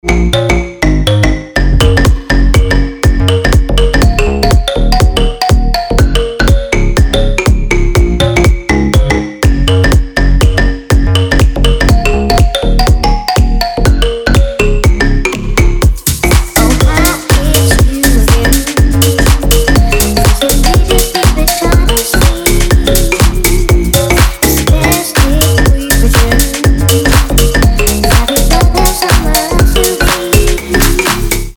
• Качество: 320, Stereo
Electronic
EDM
house
звонкие
электроника